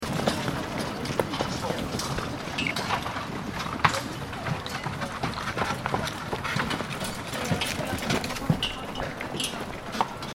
دانلود صدای کالسکه در خیابان از ساعد نیوز با لینک مستقیم و کیفیت بالا
جلوه های صوتی